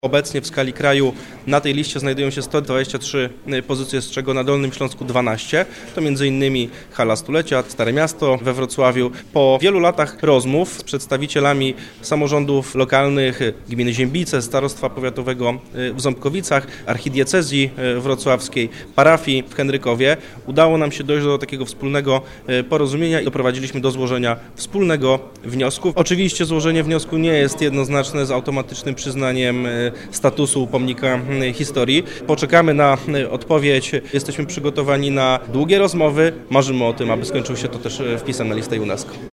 – dodaje marszałek Macko.